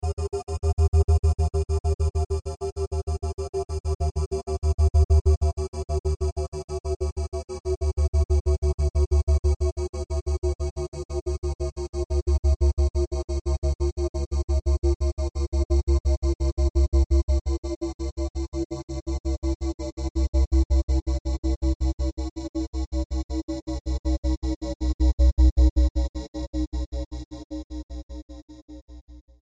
Pulsing
Amplifier Hum Descend